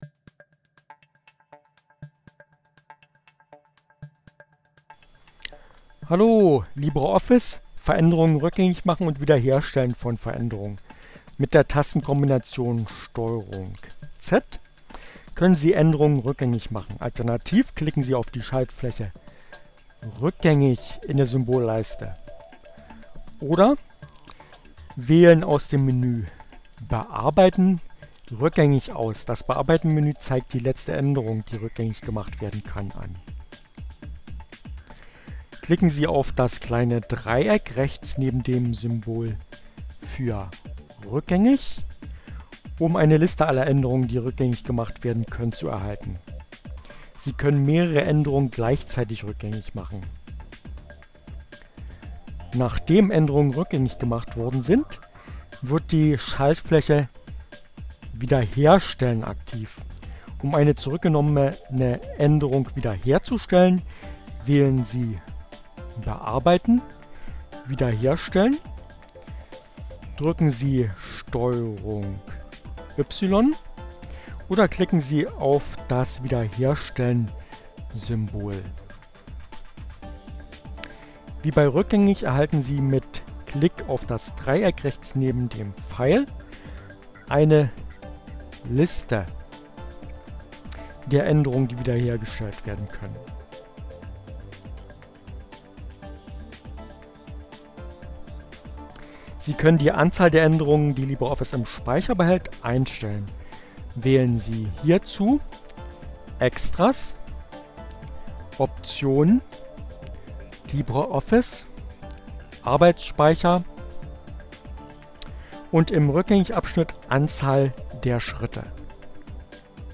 Tags: Fedora, Gnome, Linux, Neueinsteiger, Ogg Theora, ohne Musik, screencast, CC by, LibreOffice, gnome3